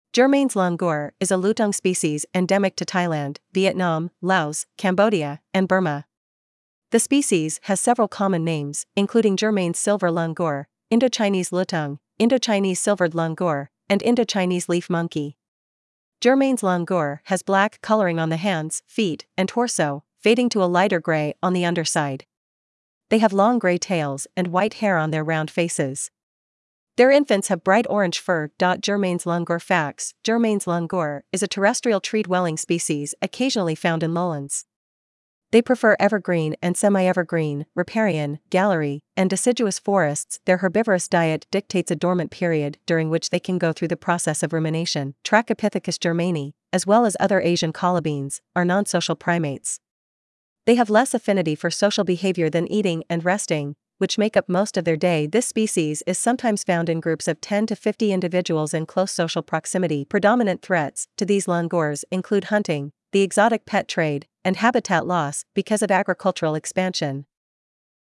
Germain's Langur
Germains-langur.mp3